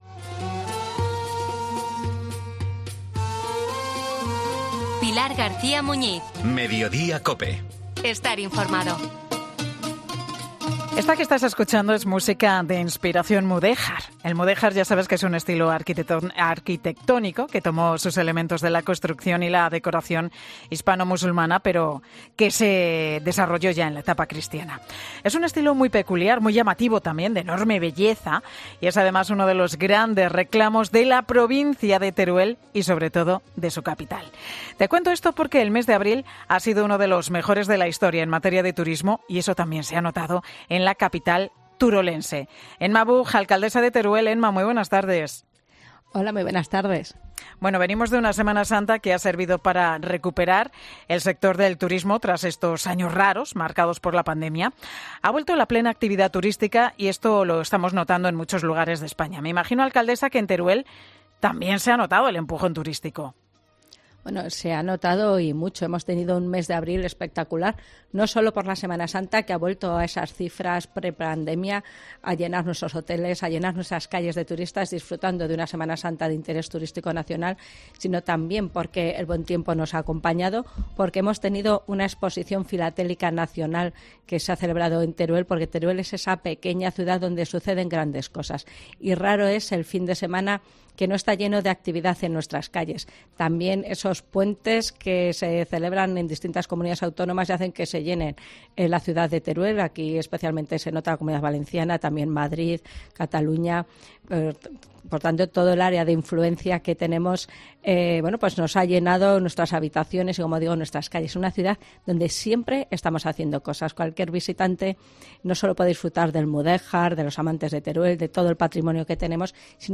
AUDIO: Escucha a Emma Buj, alcaldesa de Teruel, en 'Mediodía COPE'